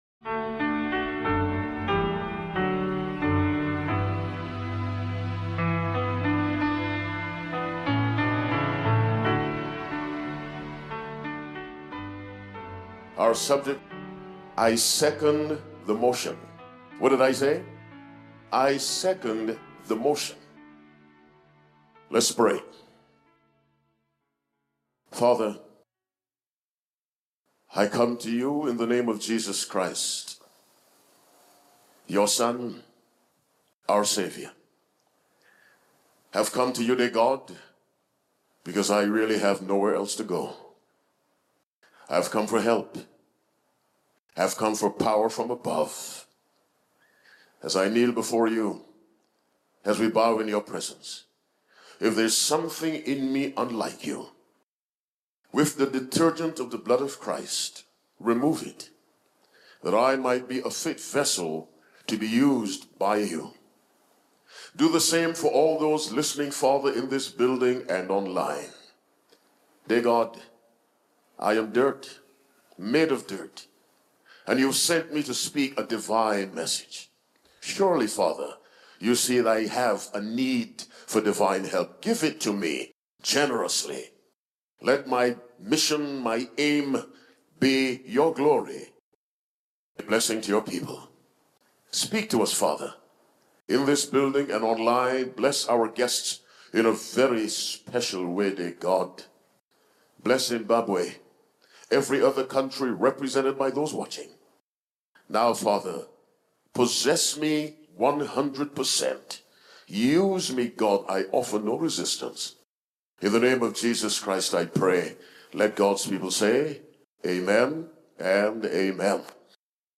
This sermon unpacks the powerful truth that genuine faith is validated not just by personal belief, but by the witness of the Father, Son, and Holy Spirit. Through biblical examples and a call to obedient living, it challenges believers to seek God's approval through fruitful, authentic faith affirmed in both heaven and earth.